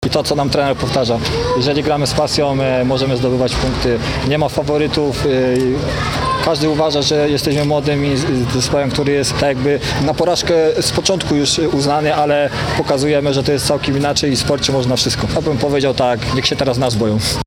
Tuż po zakończeniu spotkania